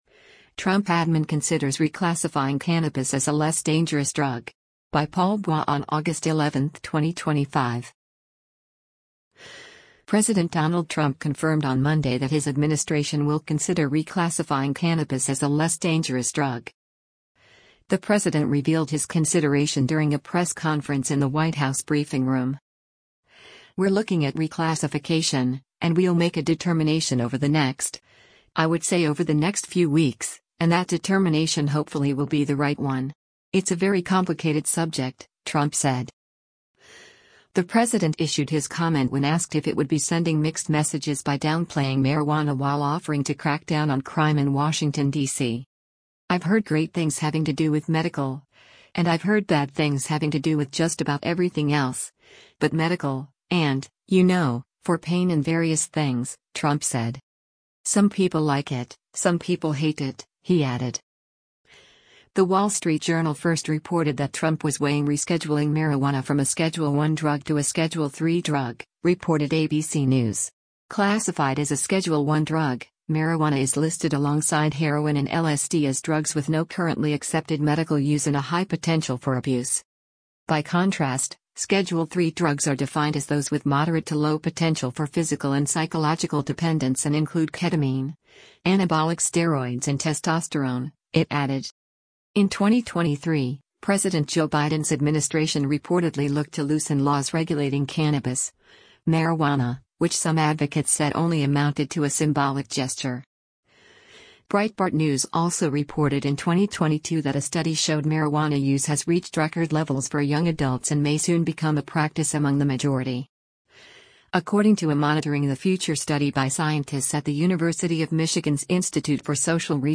The president revealed his consideration during a press conference in the White House briefing room.